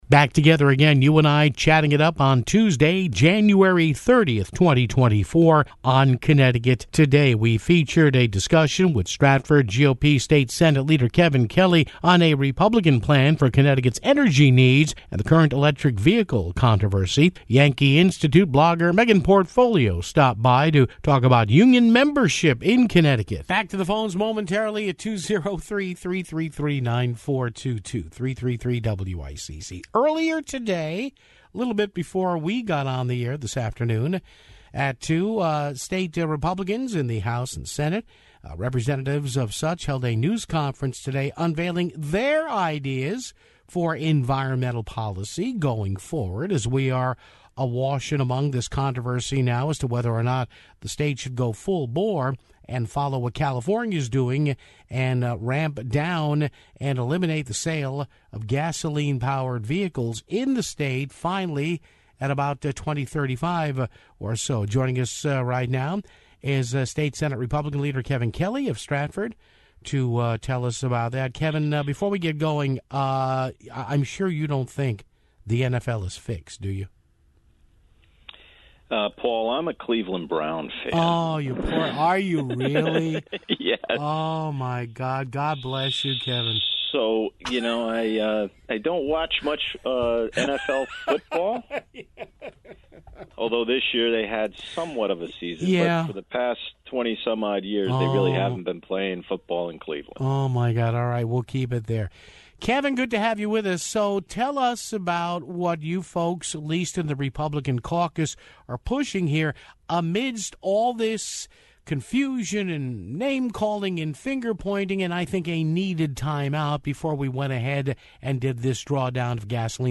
featured a chat with Stratford GOP State Sen. Kevin Kelly on a Republican plan for Connecticut's energy needs and the current electric vehicle controversy (00:23).